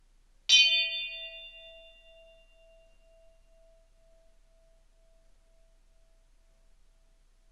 Bell 06
bell bing brass ding sound effect free sound royalty free Sound Effects